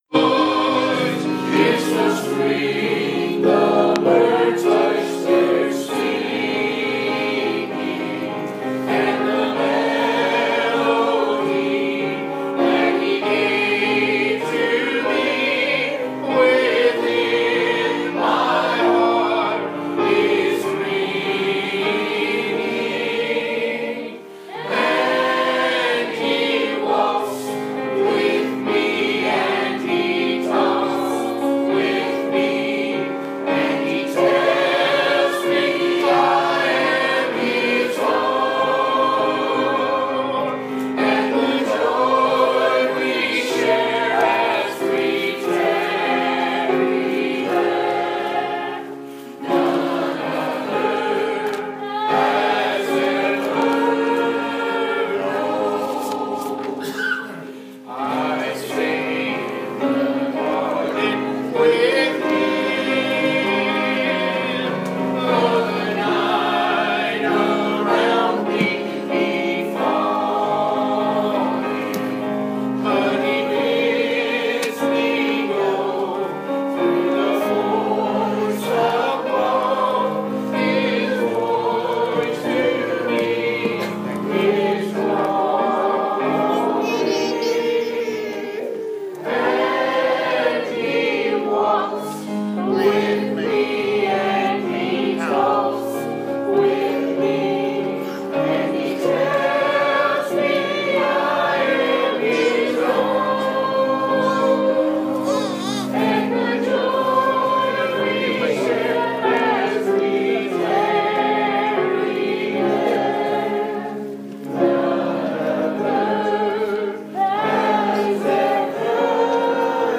Preached to the Saints at Riverview Baptist Church on April 15, 2017 at 798 Santa Fe Pike, Columbia, TN 38401